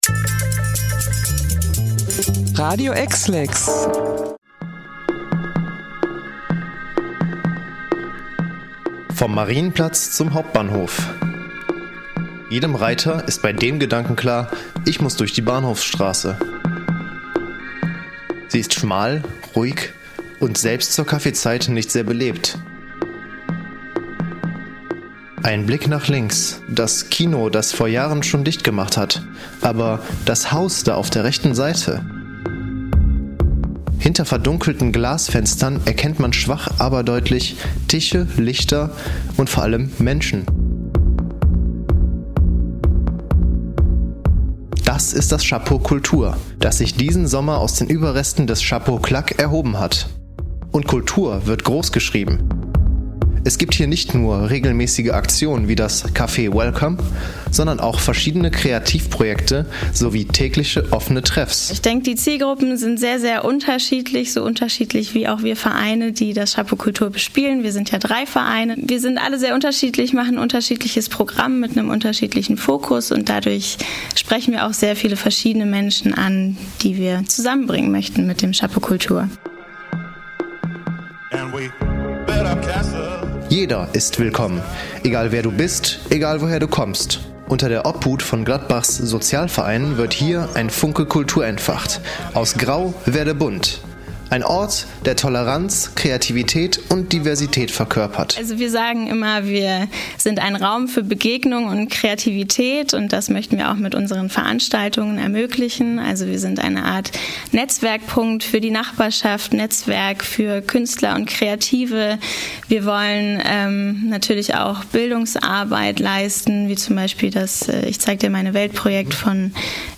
Bericht-Chapeau-Kultur-RG.mp3